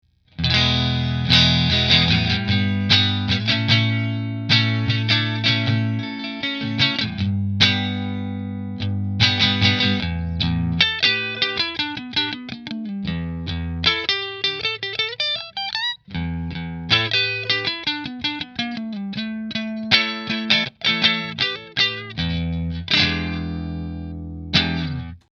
Fender Partscaster Position 1 Through Fender